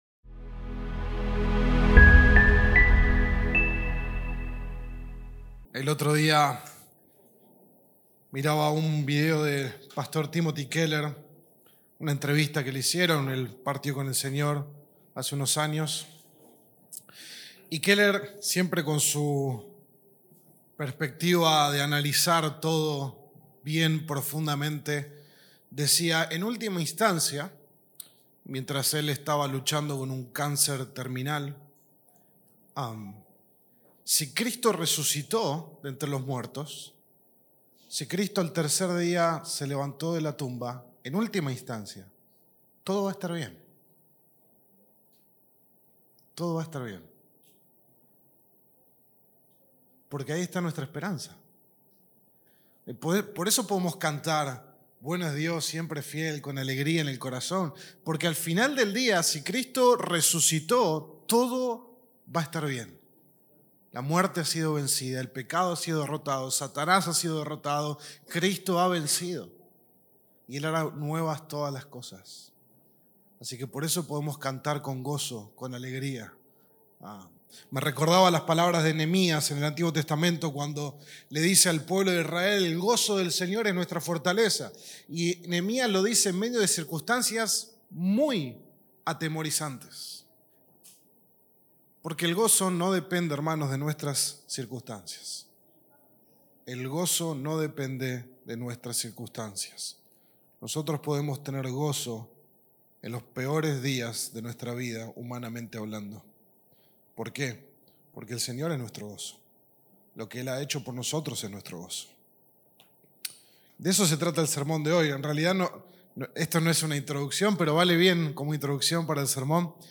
Sermon-5-de-Octubre-de-2025.mp3